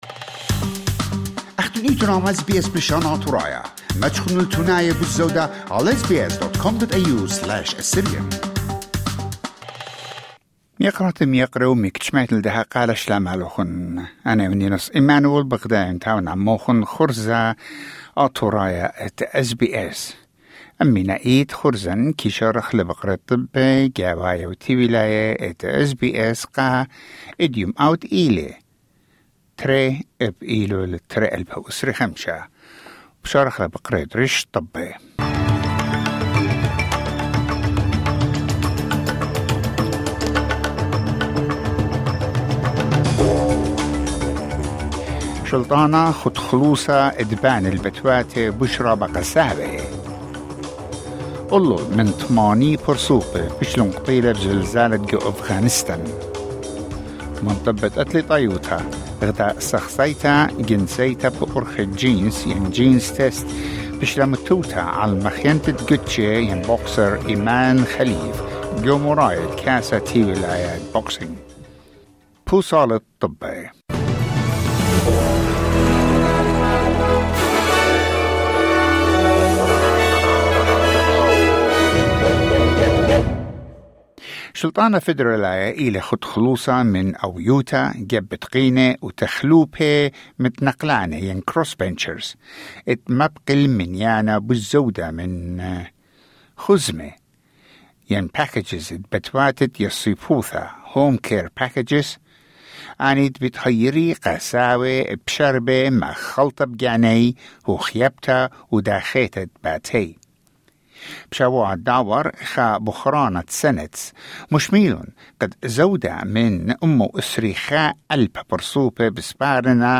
News Bulletin: 2 September 2025